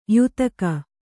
♪ yutaka